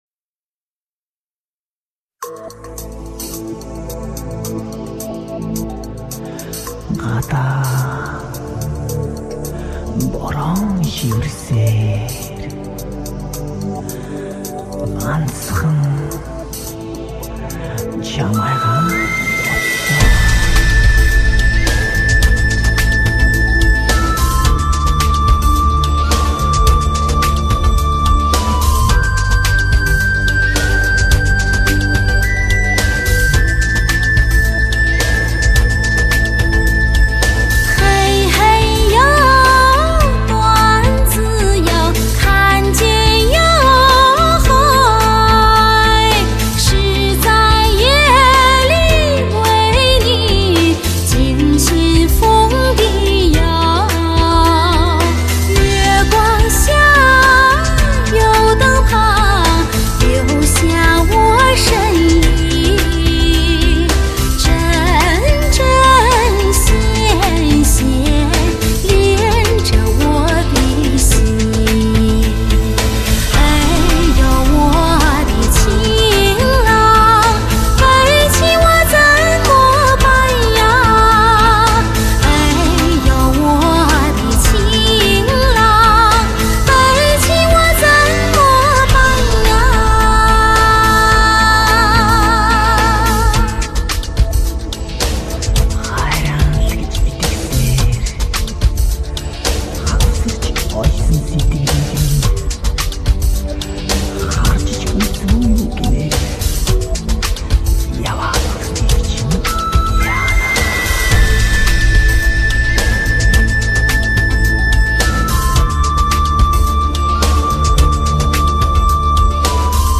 试听低音质128K
草原的风光最美丽,草原的歌声最悠扬。
清新的风格，值得欣赏。